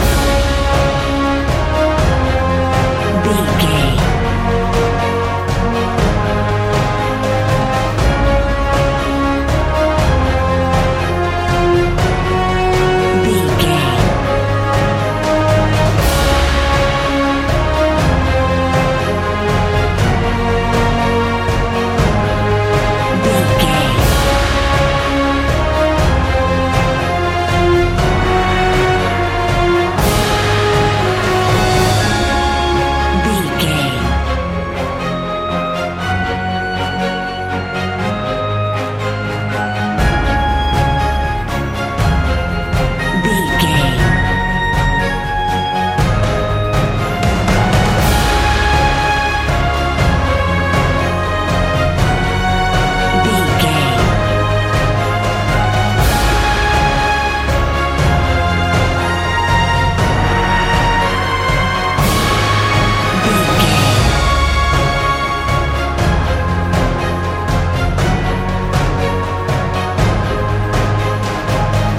Uplifting
Ionian/Major
C♯
energetic
epic
brass
drums
piano
strings